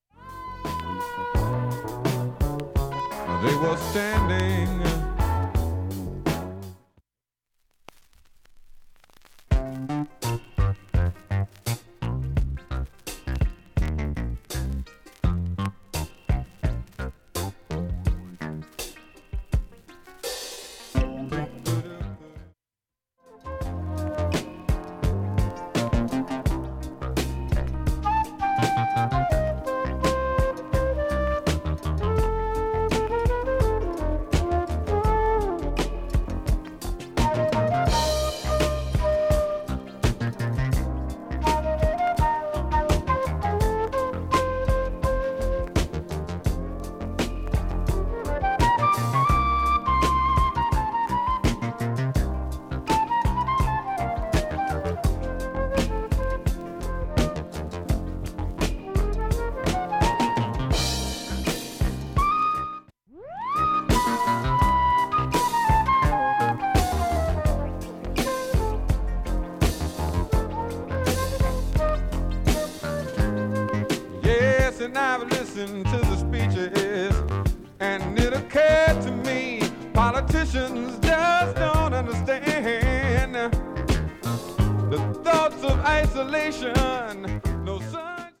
途中数回３箇所聞こえないプツ程度。
盤面ほかきれいです、音質良好全曲試聴済み。
A-1中盤にかすかなプツが３回出ます。
B-1始めにかすかなプツが２回出ます。